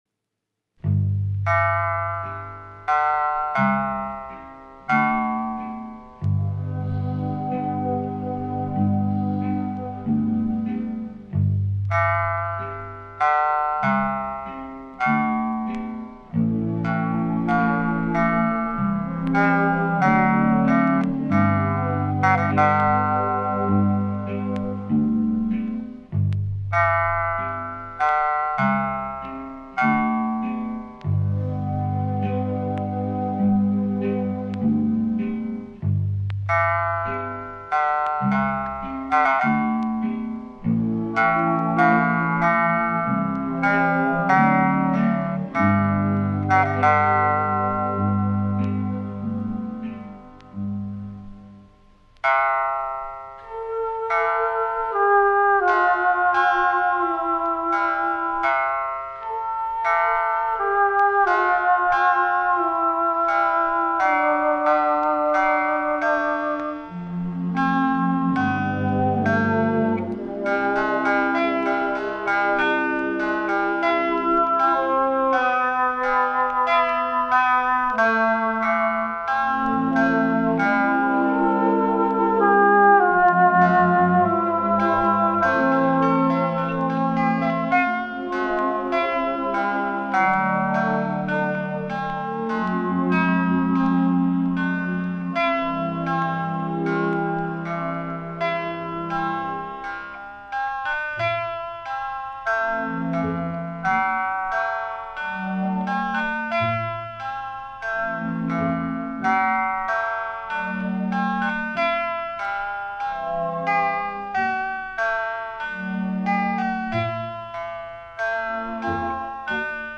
It’s easy to hear why; it sounds just like it in parts.